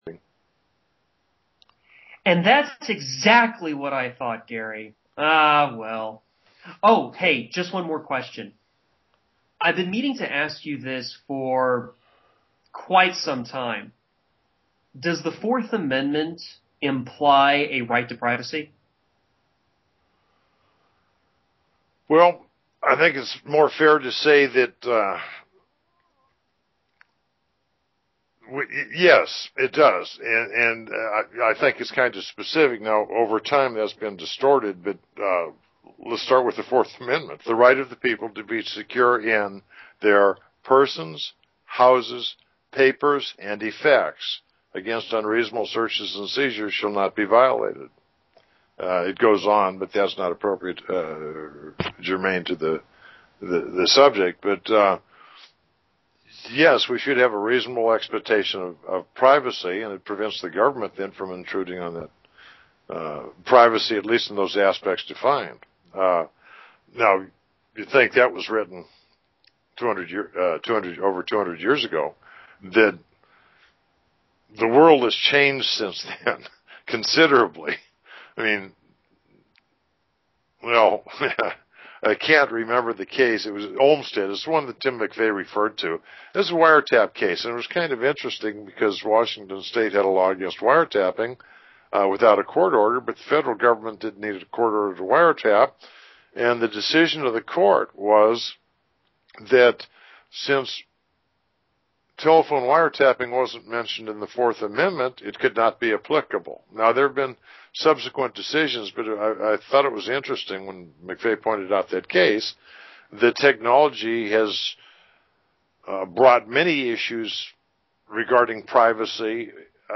In the tradition of classical podcasting, "Behind Enemy Lines" is a series brought to you from the Team of Outpost of Freedom Radio. Beginning with a question on a relevant subject between two patriots, the conversation becomes serendipitous, as the discussion continues in an informal conversational style. Unlike the more structured format of the regular OPF Radio shows, Behind Enemy Lines is a more naturally flowing discussion regarding topics of interest to the Patriot Community.